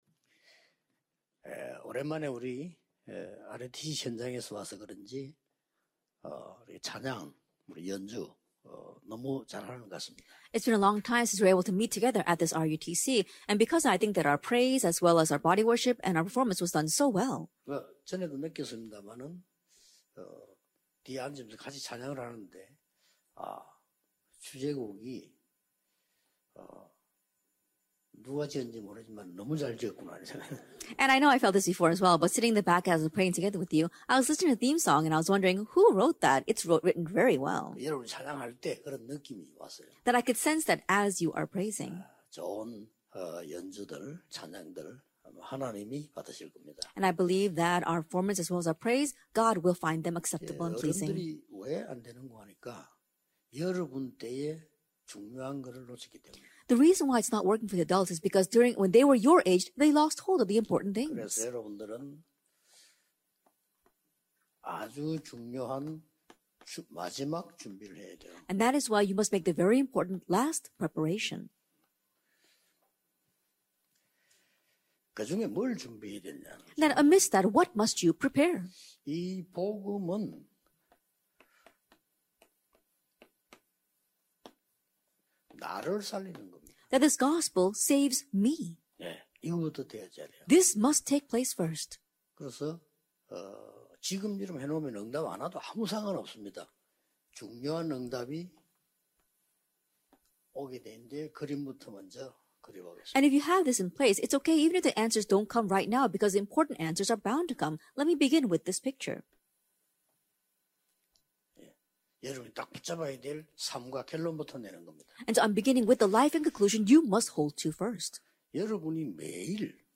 2022 World Youth Retreat Team Leader Message - Last Preparation [Gospel - Me]